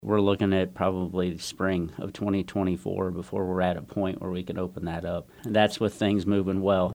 That’s Deputy City Manager Jason Hilgers on KMAN earlier this week.